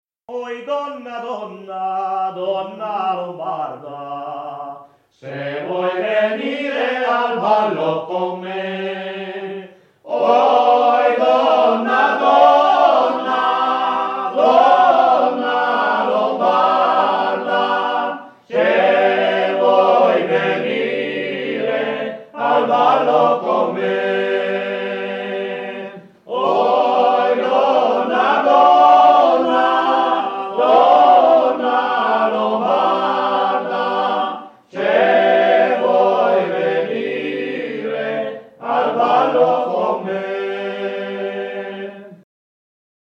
Donna lombarda / [registrata a Venasca (CN), nel 1988, cantata dai Cantori di Venasca]
Esecutore: Cantori di Venasca